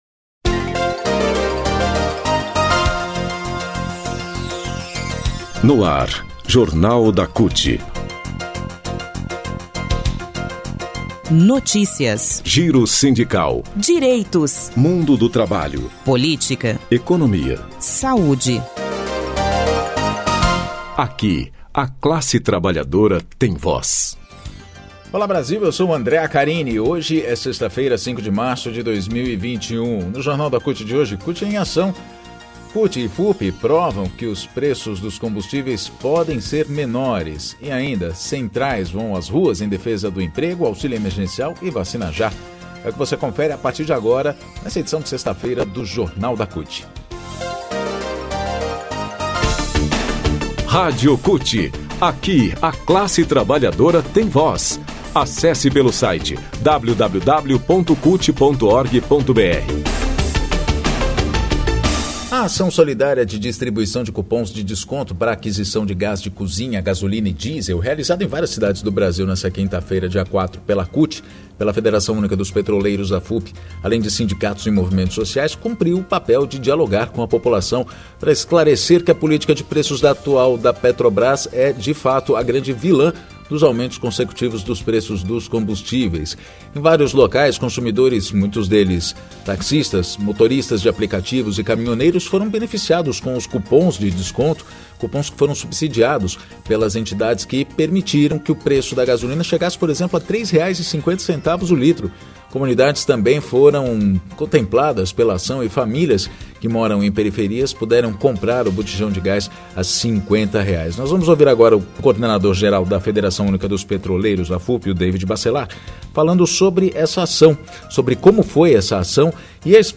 Jornal de rádio da CUT